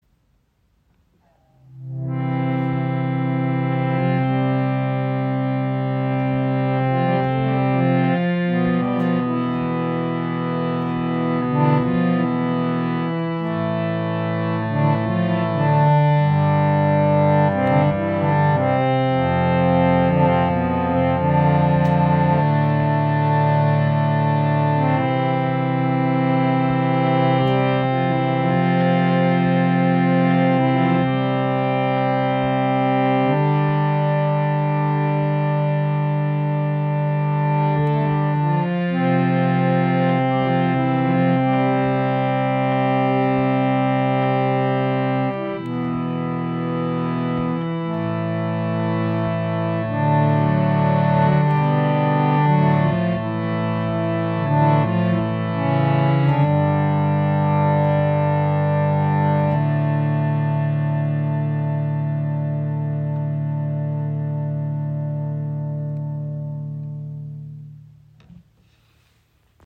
Reise Harmonium von "Radha" mit 2½ Oktaven | Teakholz Edition | 440 Hz
• Icon Warmer, tragender Klang mit Seele für Gesang und Klangarbeit
Sein Klang ist warm und sanft, lässt sich sowohl sehr leise als auch kräftig spielen.
Die speziell eingestellten Federn sorgen für einen weichen, lang anhaltenden Klang – ohne störende Pumpgeräusche.
• Pro Ton zwei Zungen im mittleren und tiefen Register